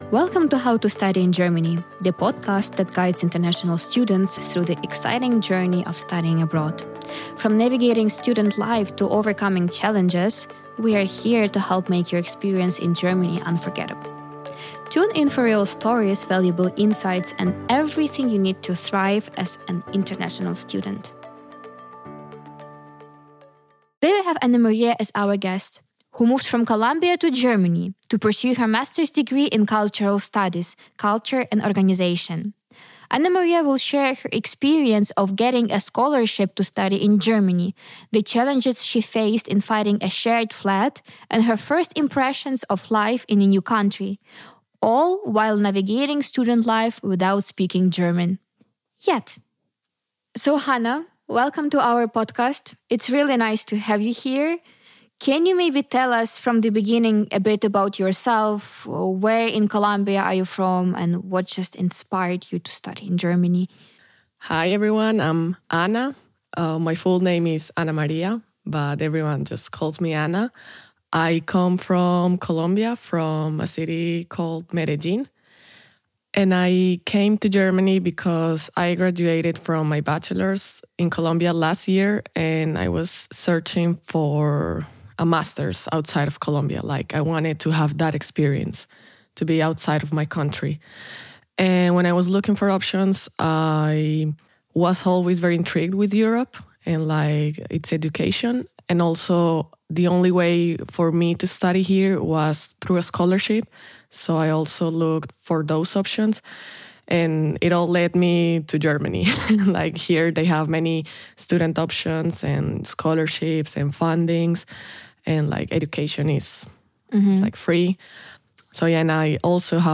We also talk about language barriers, cultural shocks, and her tips for future international students. Tune in for an inspiring and eye-opening conversation!